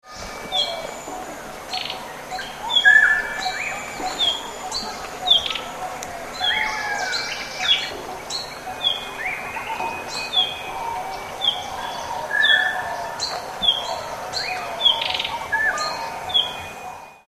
It has been edited slightly with reduction of unwanted background sounds to some extent. The calls heard are the whistled ‘pweep’ sound and the rattle ‘trirrri’, and sounds of some other birds are also evident in background of the track (i.e. Coppersmith Barbet, Black-headed Oriole, Forest Wagtail, Indian Peafowl and Brown-headed Barbet ).
Yellow-rumped-Flycatcher-calls.mp3